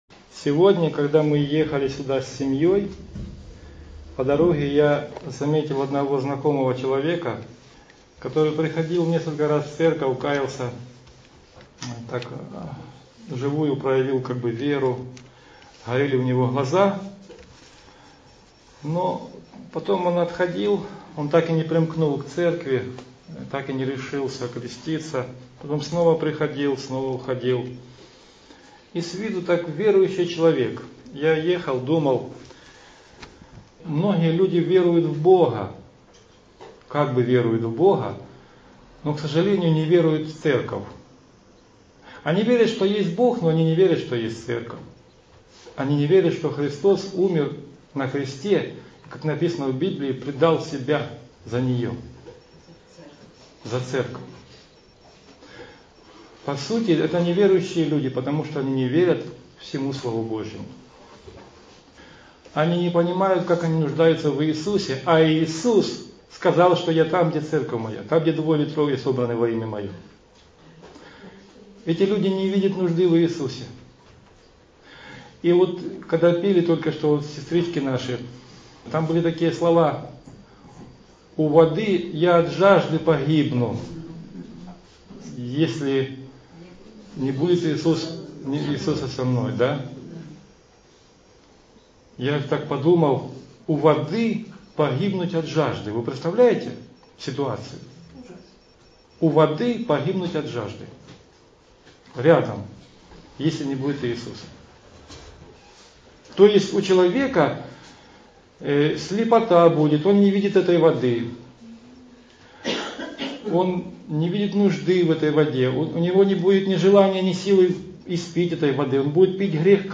Так же в проповеди освещены вопросы о разводах, и супружеской верности. Аудио-проповедь